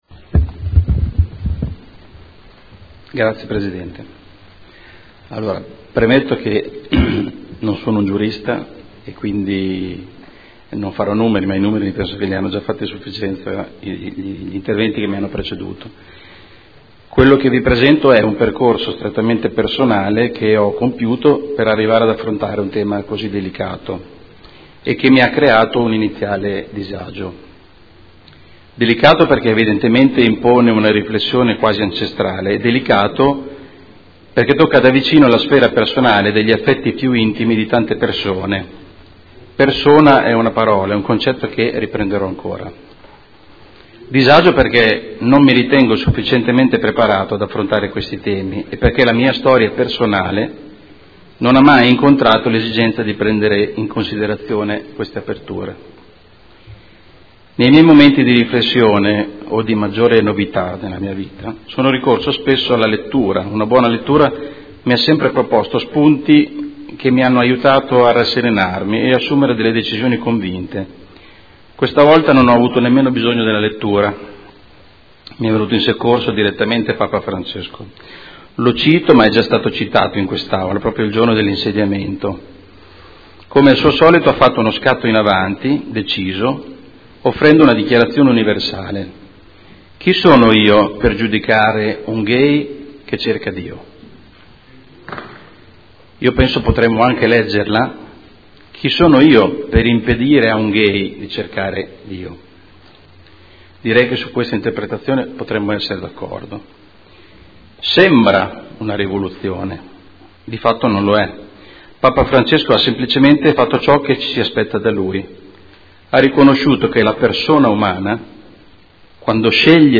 Carmelo De Lillo — Sito Audio Consiglio Comunale